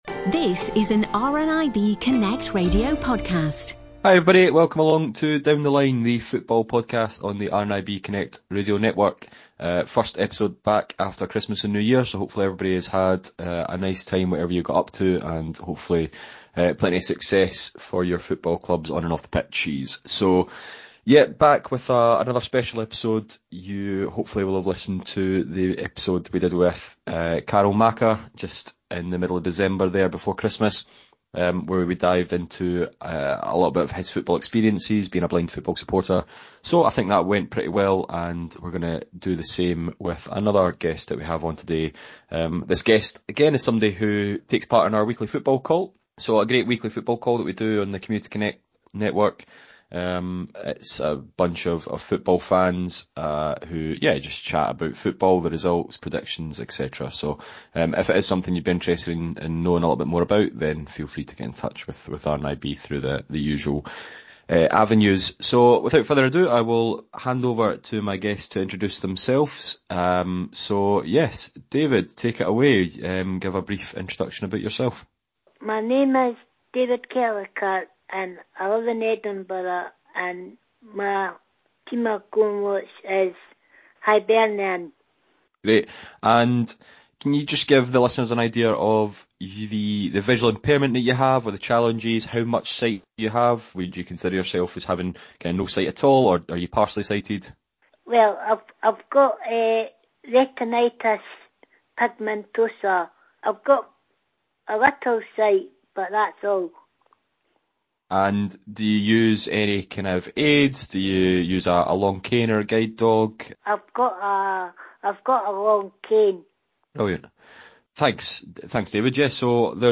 In our weekly football round up ‘Down The Line’, football-loving members of RNIB ‘Community Connections’ telephone groups get together each week to talk about the latest going on in leagues across Scotland and England.